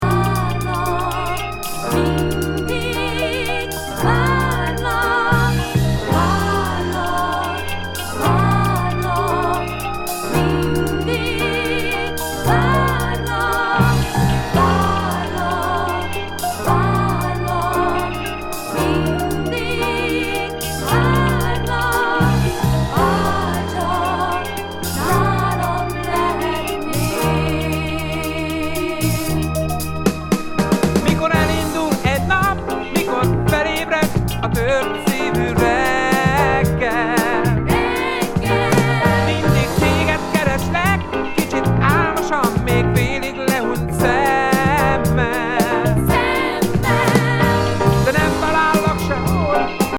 メロウ・ディスコ